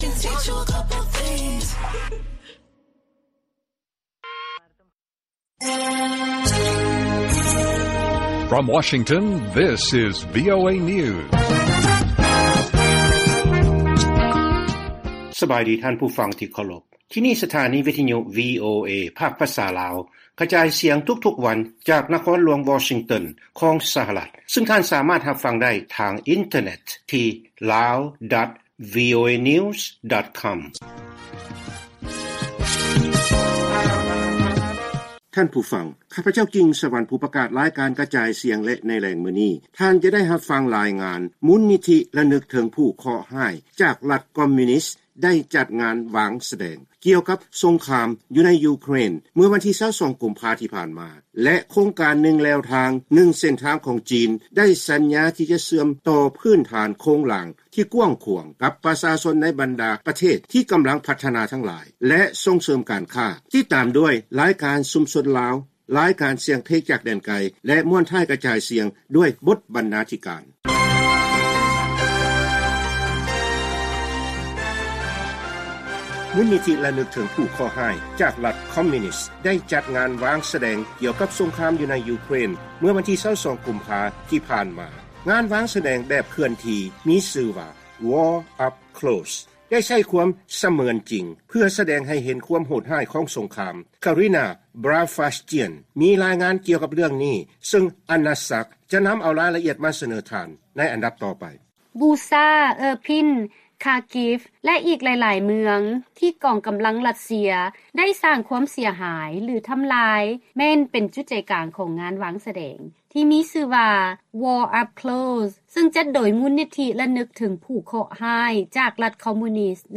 ລາຍການກະຈາຍສຽງຂອງວີໂອເອລາວ: ຄວາມຢ້ານກົວ ຄວາມຫວັງ ແລະຄວາມຜິດຫວັງ ເຊື່ອມໂຍງ ກັບໂຄງການທາງລົດໄຟອິນໂດເນເຊຍ-ຈີນ